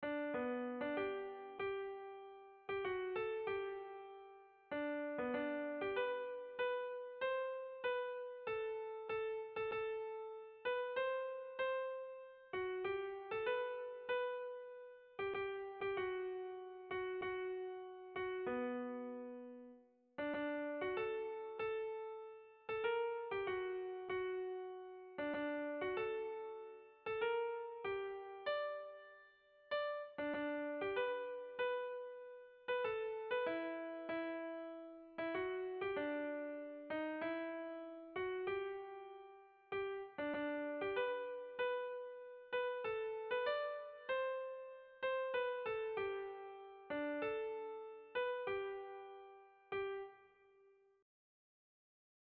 Erlijiozkoa
Zortziko handia (hg) / Lau puntuko handia (ip)
ABDE1E2